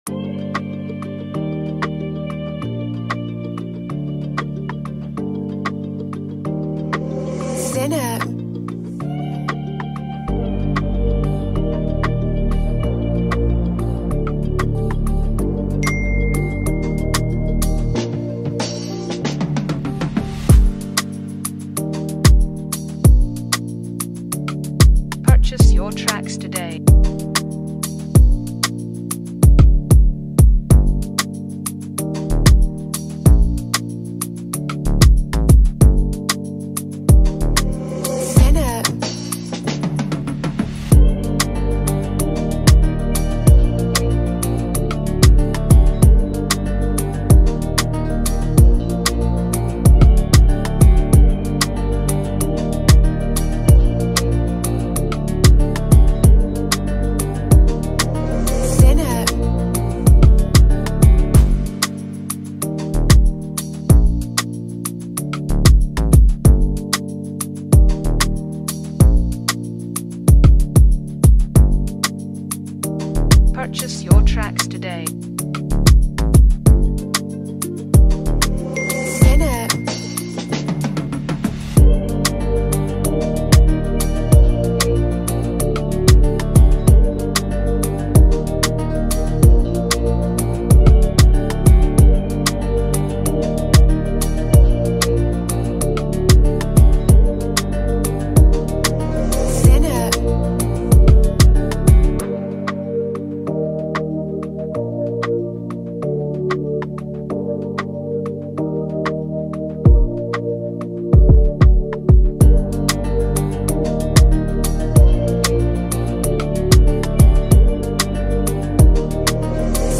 a vibrant instrumental track